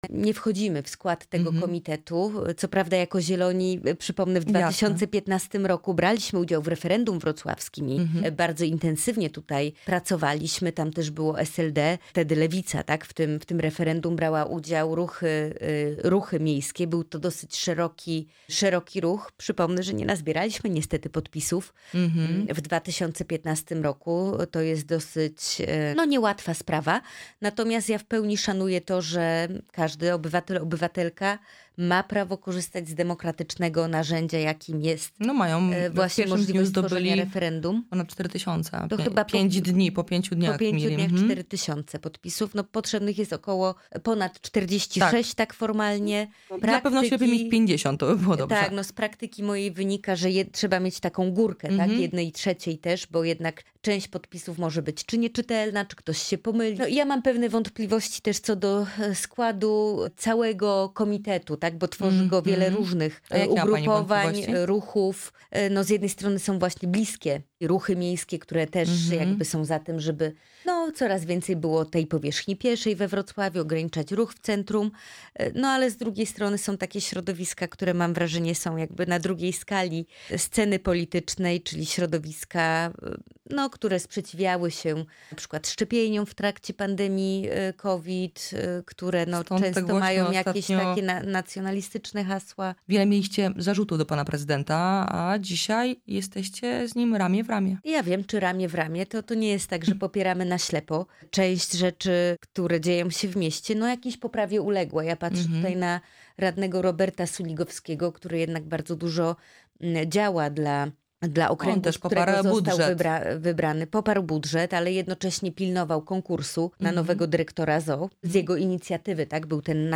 Samochody elektryczne, Zielony Ład, projekt obywatelski „Stop łańcuchom”, zakaz polowania we Wrocławiu, interwencja w sprawie używania fajerwerków w Ślężańskim Parku Krajobrazowym oraz referendum ws. odwołania prezydenta Jacka Sutryka – te zagadnienia poruszyliśmy z posłanką na Sejm Małgorzatą Tracz z KO (partia Zieloni).
Z posłanką rozmawialiśmy o referendum za odwołaniem Jacka Sutryka.